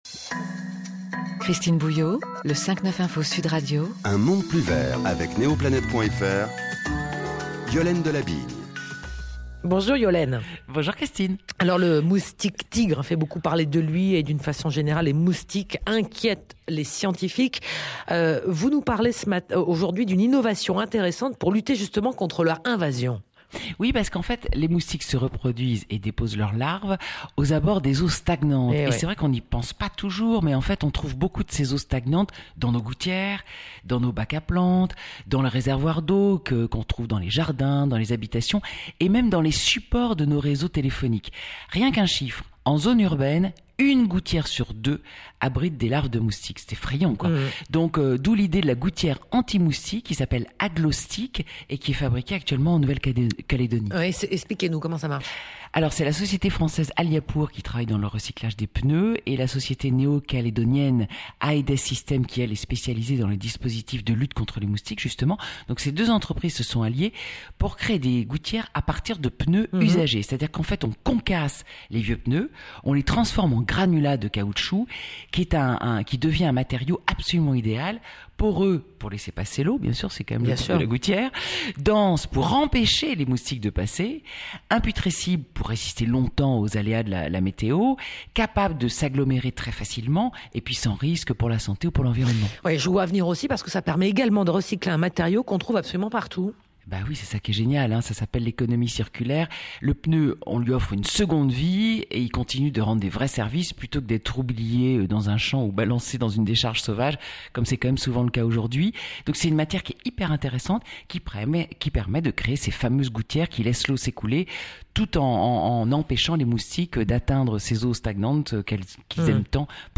585Cette chronique a été diffusée le 11 février sur Sud Radio en partenariat avec ENGIE acteur de la transition énergétique